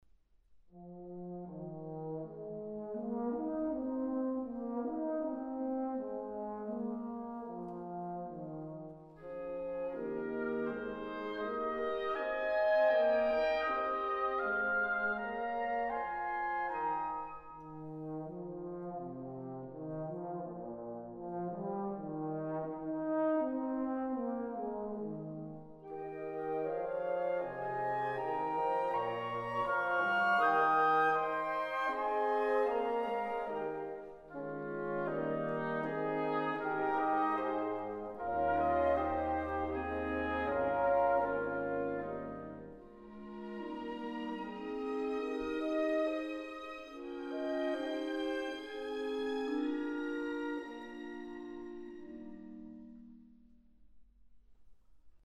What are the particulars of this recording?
Recording: Katharina-Saal, Stadthalle Zerbst, 2025